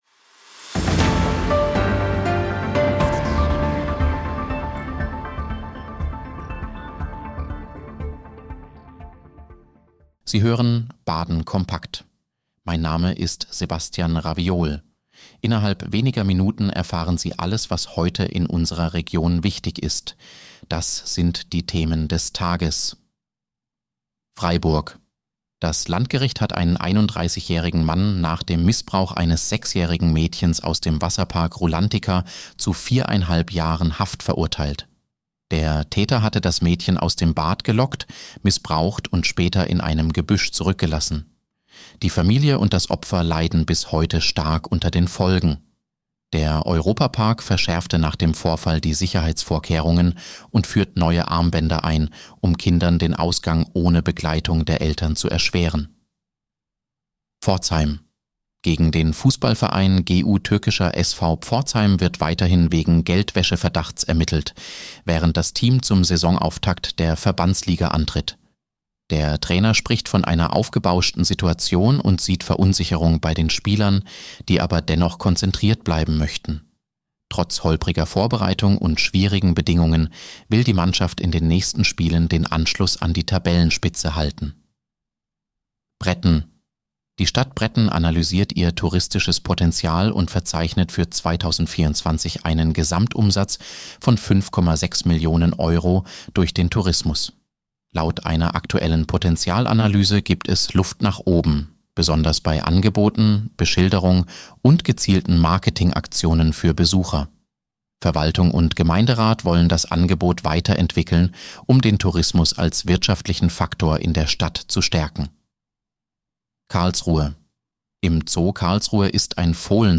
Nachrichtenüberblick: Urteil nach Rulantica-Missbrauchsfall